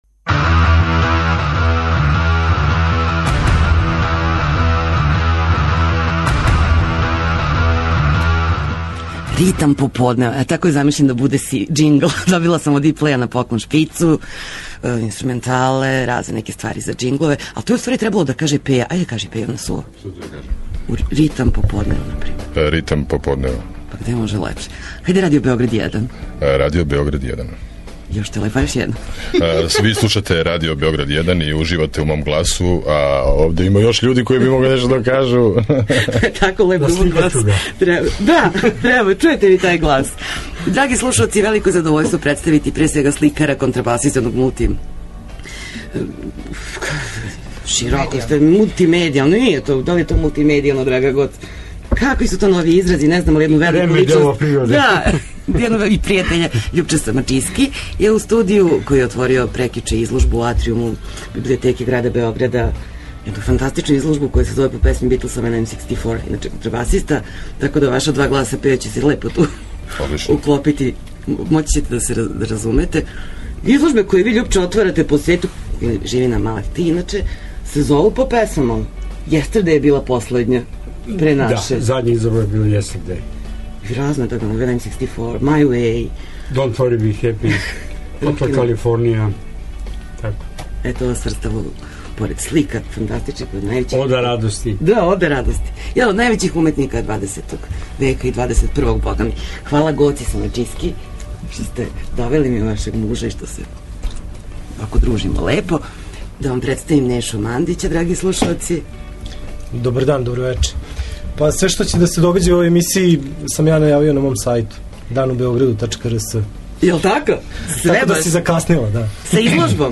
Он је прави саговорник на теме: нови сајтови, концерти и културни догађаји.